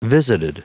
Prononciation du mot visited en anglais (fichier audio)
Prononciation du mot : visited